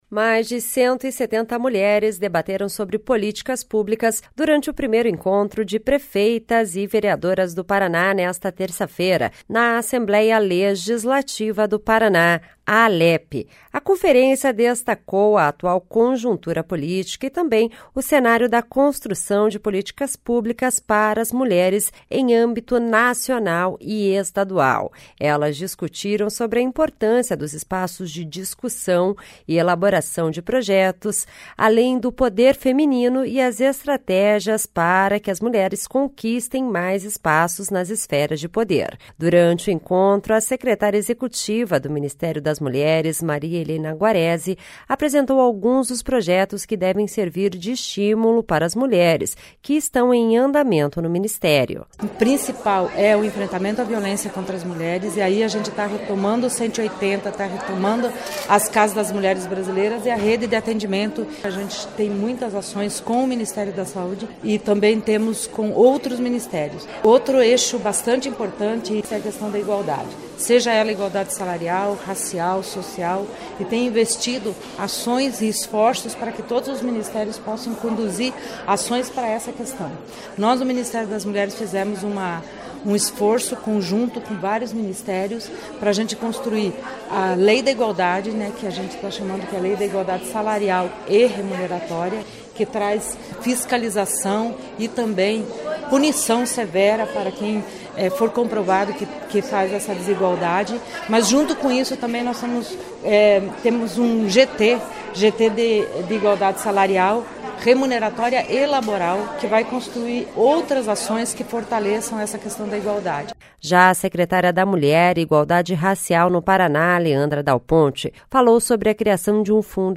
Já a secretária da Mulher e Igualdade Racial no Paraná, Leandre  Dal Ponte, falou sobre a criação de um  Fundo Estadual para as políticas públicas voltadas para mulheres no Orçamento do Governo.
A deputada estadual Mabel Canto (PSDB) falou sobre a criação da Bancada Feminina na Assembleia, e como esta iniciativa pode servir de estímulo para que mais mulheres se candidatem a cargos públicos.